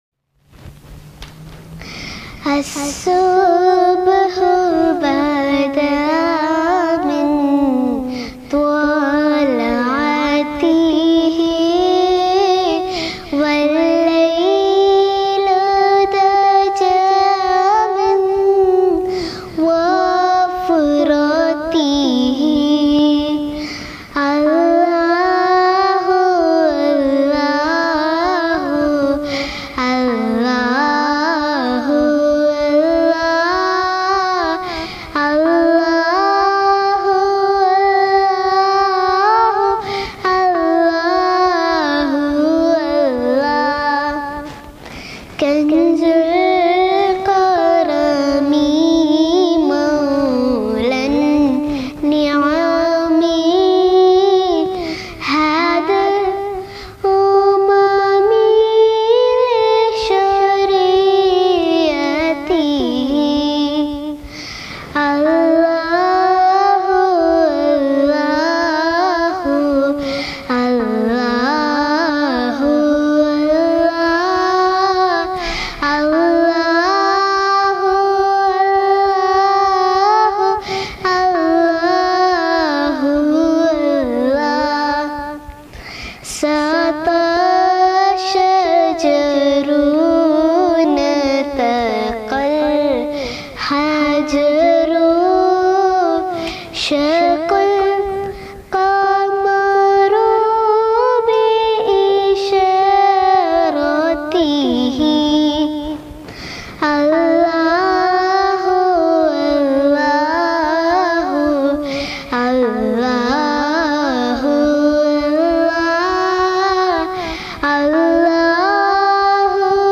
Arabic Naat
Heart-Touching Voice